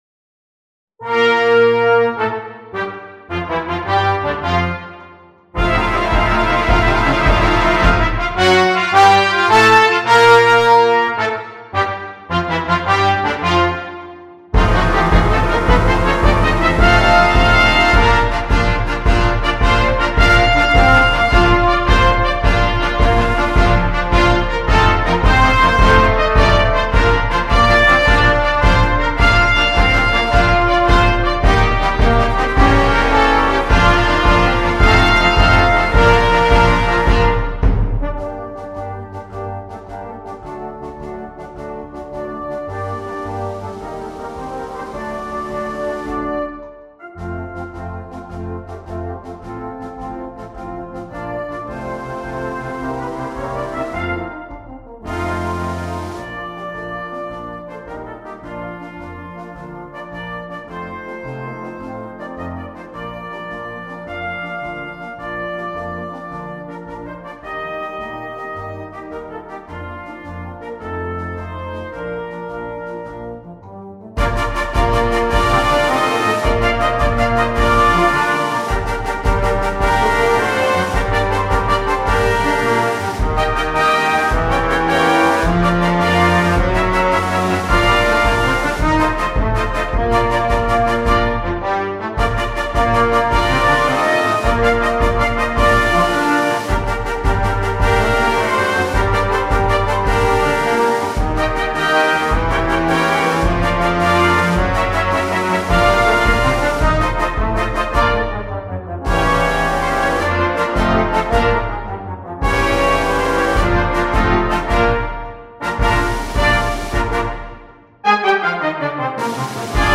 “Battle of Eureka” (contest march
rendered using Sibelius software with NotePerformer